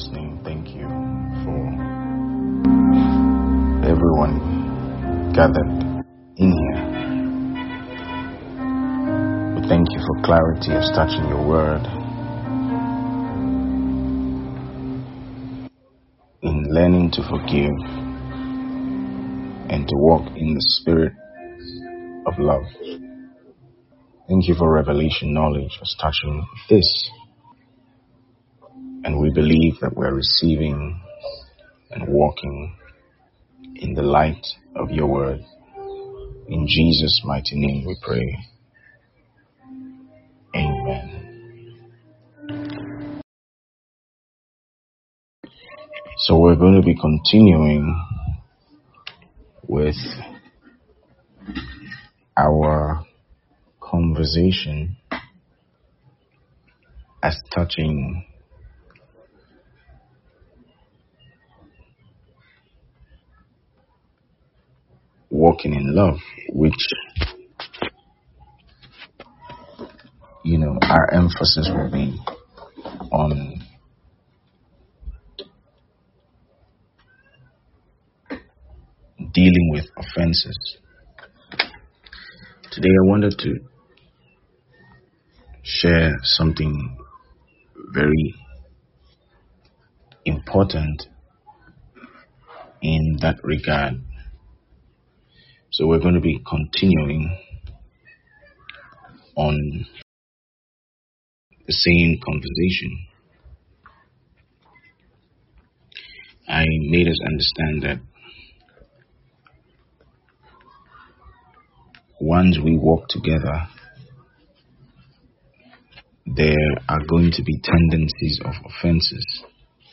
A 3-part sermon series on Dealing With Offences